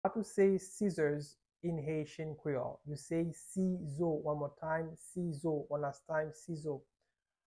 How to say "Scissors" in Haitian Creole - "Sizo" pronunciation by a private Haitian Creole teacher
“Sizo” Pronunciation in Haitian Creole by a native Haitian can be heard in the audio here or in the video below:
How-to-say-Scissors-in-Haitian-Creole-Sizo-pronunciation-by-a-private-Haitian-Creole-teacher.mp3